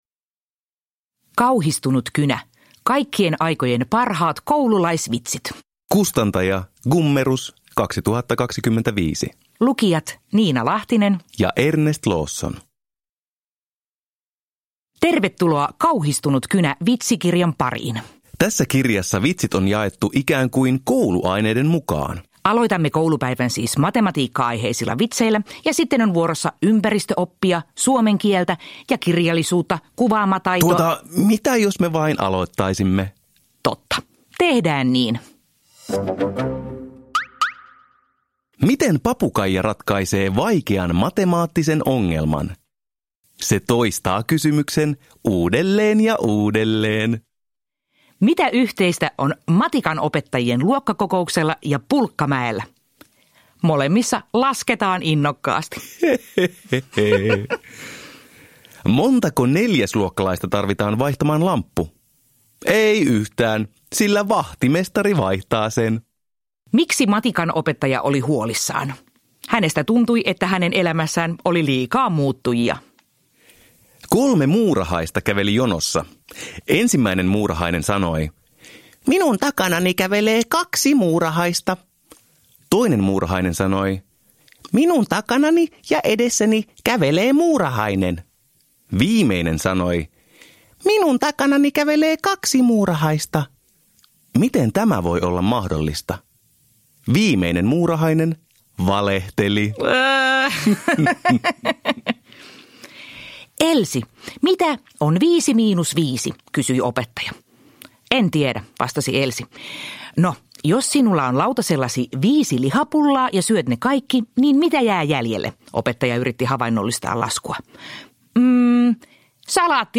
Kauhistunut kynä – Ljudbok